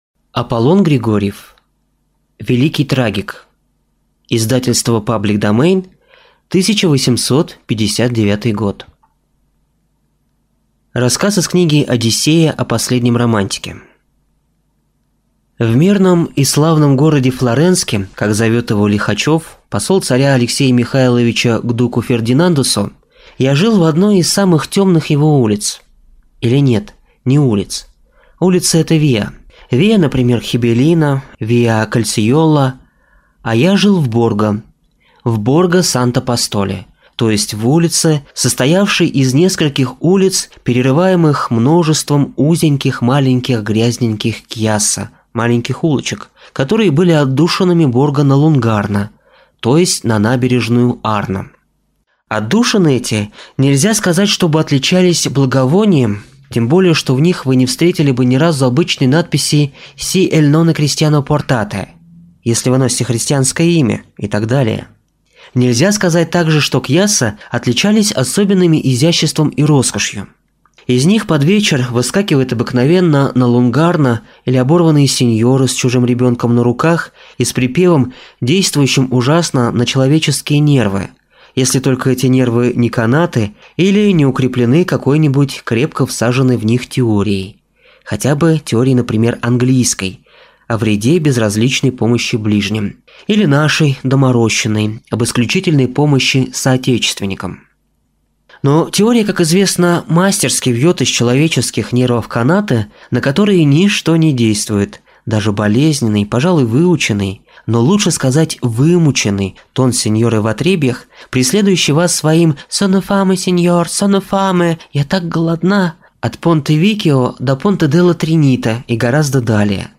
Aудиокнига Великий трагик